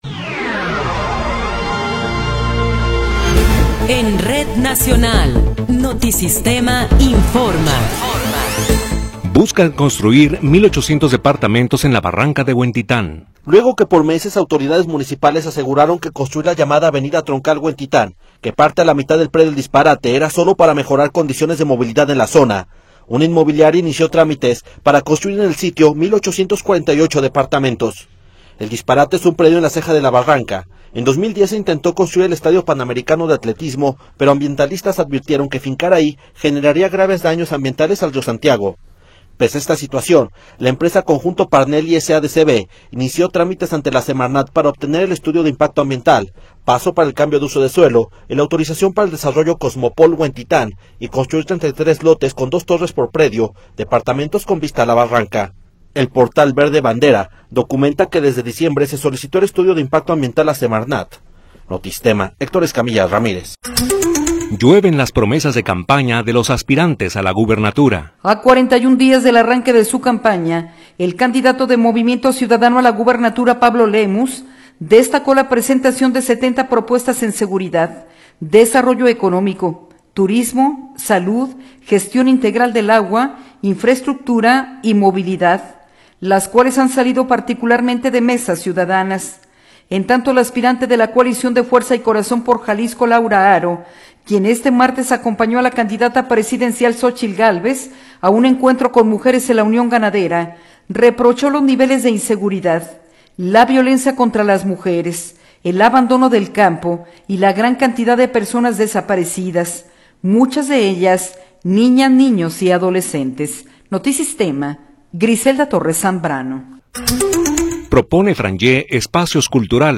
Noticiero 19 hrs. – 16 de Abril de 2024
Resumen informativo Notisistema, la mejor y más completa información cada hora en la hora.